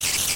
Звуки пауков
Все звуки оригинальные и взяты прямиком из игры.
Шипение/Получение урона №4
SpiderIdle4.mp3